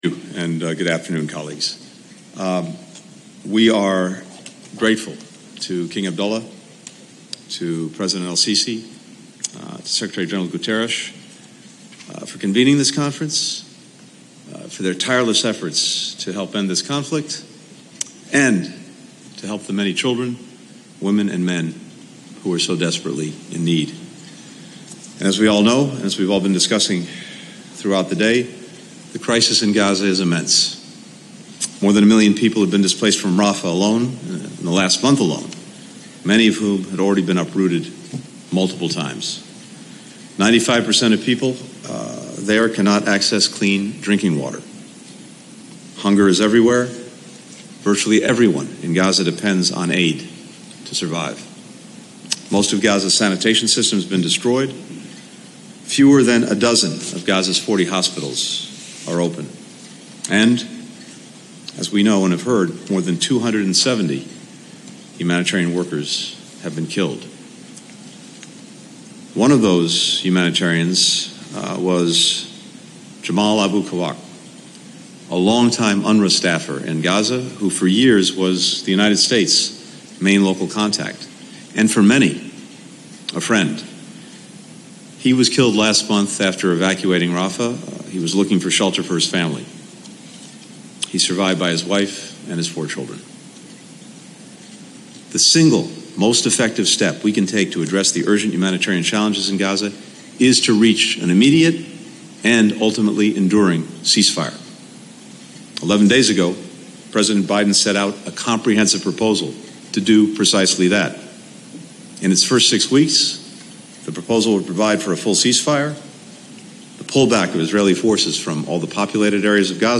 Antony J. Blinken Remarks at the �Call for Action: Urgent Humanitarian Response for Gaza� Conference (transcript-audio-video)